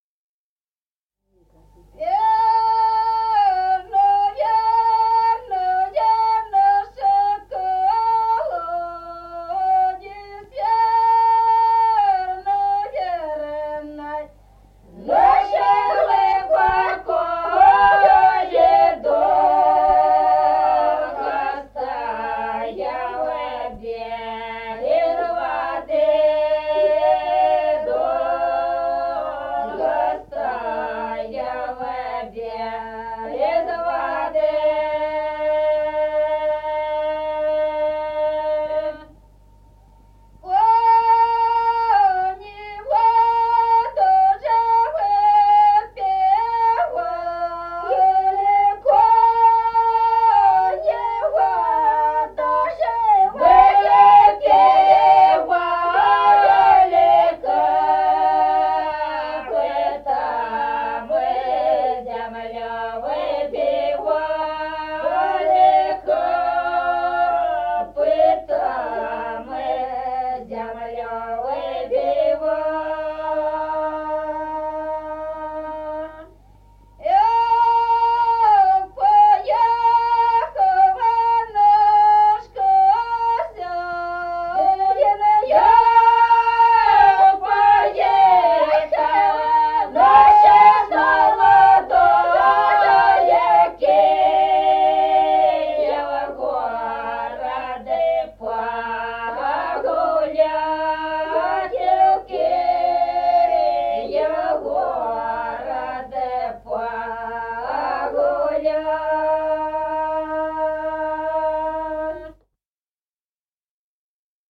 Народные песни Стародубского района «Верный наш колодезь», карагодная.
1953 г., с. Остроглядово.